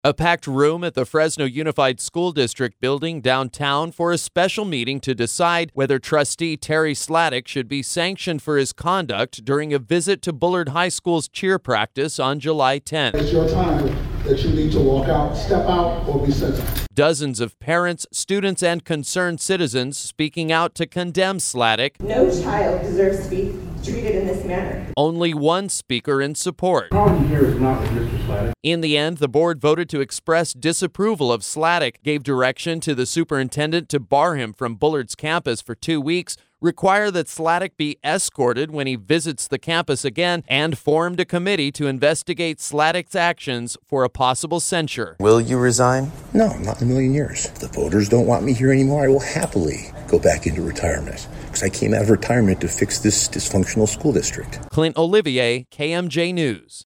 A packed room at the Fresno Unified School District building downtown for a special meeting to decide whether Trustee Terry Slatic should be sanctioned for his conduct during a visit to Bullard High School’s cheer practice on July 10th.